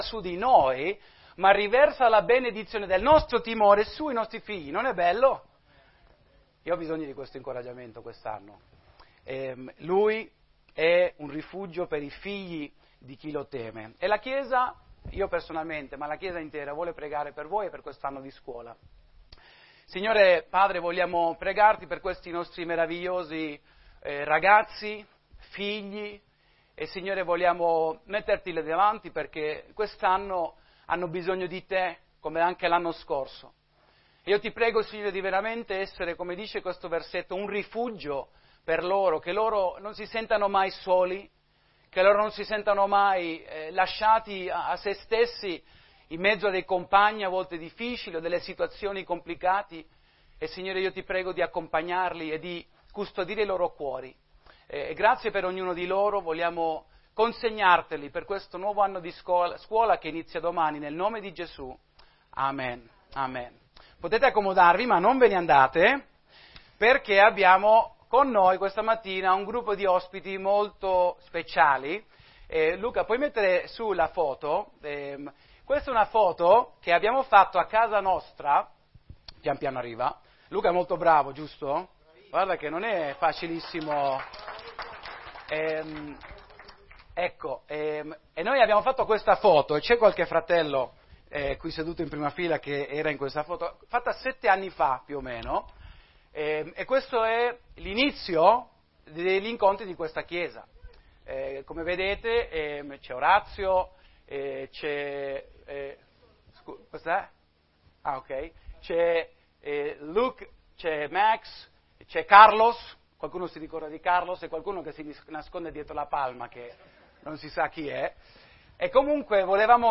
Temi: chiesa, Consacrazione, corsa cristiana, crescita, fede, Predicazione, vita Spirituale